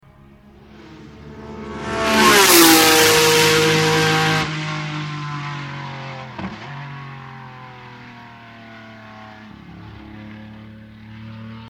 70s Ferrari 512s